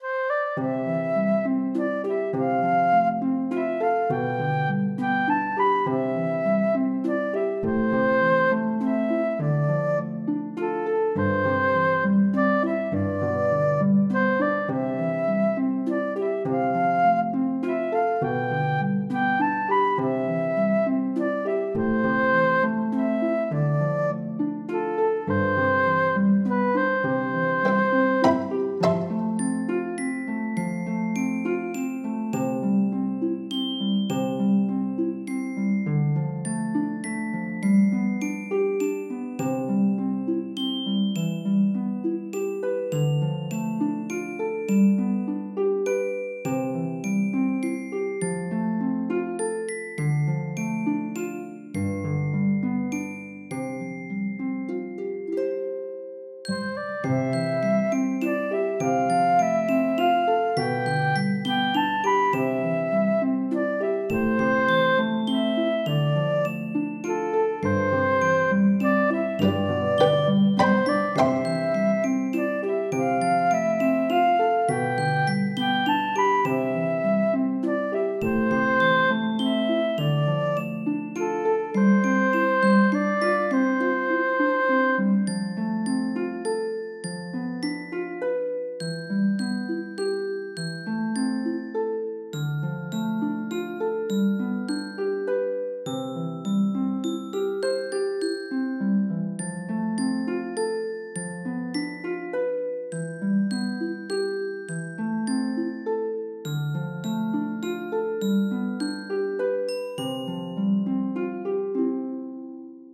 ファンタジー系フリーBGM｜ゲーム・動画・TRPGなどに！
冬っぽ～い。スレイベルを入れるとクリスマス感も出ますね。